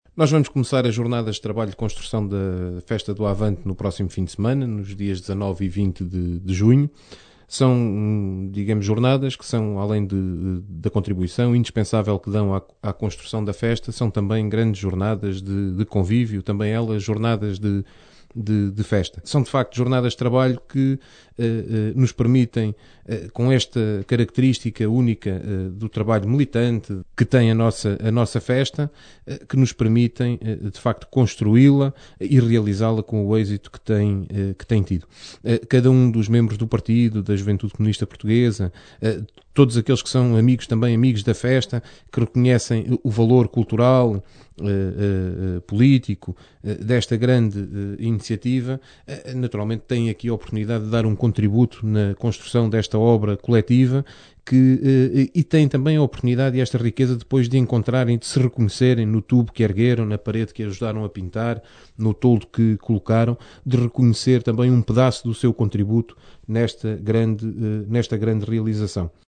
Declaração